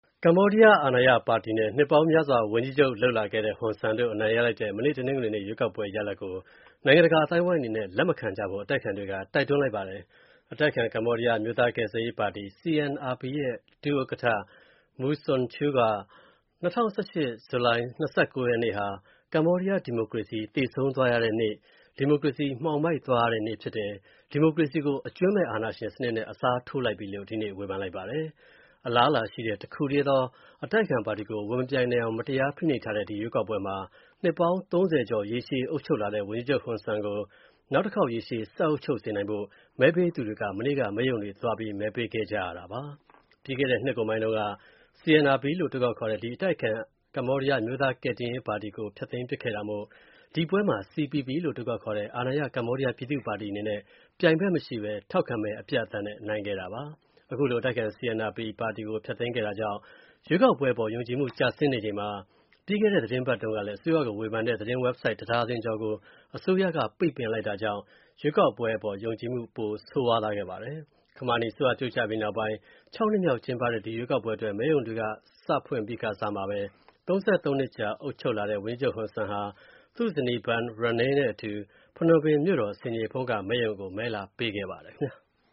အတိုက်အခံ ကမ္ဘောဒီးယား အမျိုးသားကယ်ဆယ်ရေးပါတီ CNRP ရဲ့ ဒု ဥက္ကဌ Mu Sochua ရဲ့ သတင်းစာရှင်းလင်းပွဲ ( ဇူလိုင်လ ၃၀-၂၀၁၈)